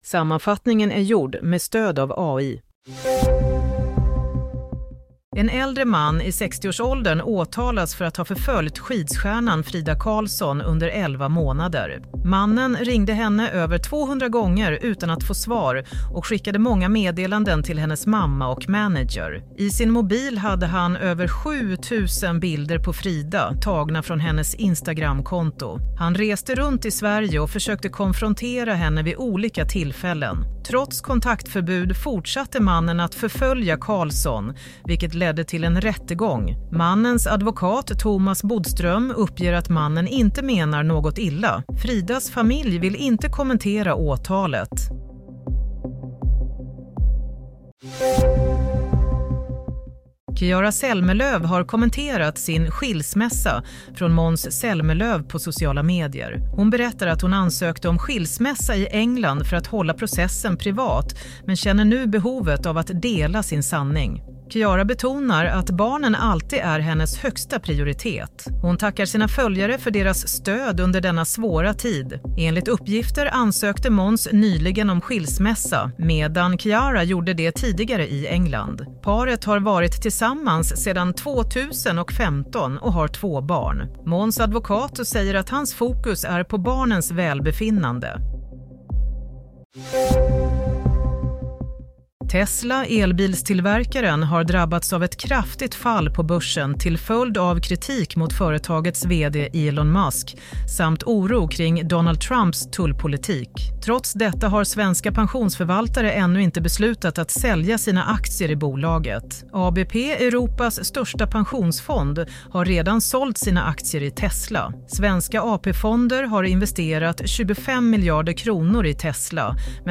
Nyhetssammanfattning - 18 mars 16:00